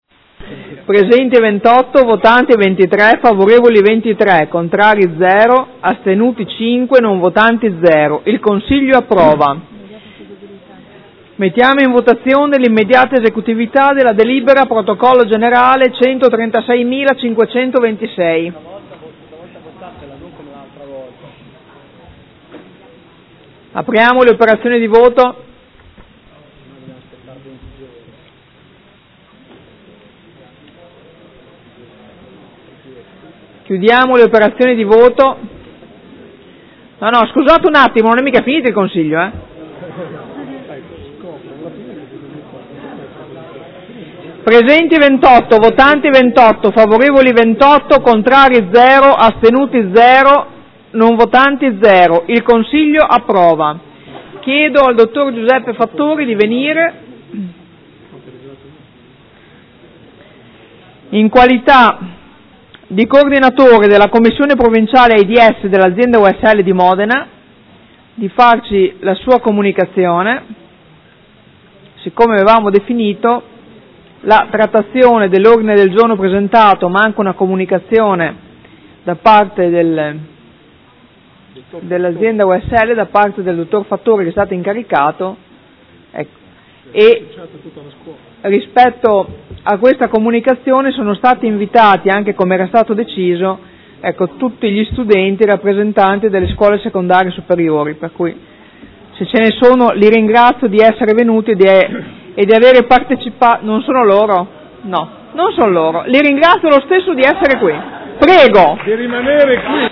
Presidentessa — Sito Audio Consiglio Comunale
Seduta del 26/11/2015 Mette ai voti Bilancio di Previsione Armonizzato 2015-2017 – Piano Investimenti 2015-2017 – Programma Triennale dei Lavori Pubblici 2015-2017 – Variazione di Bilancio n. 5.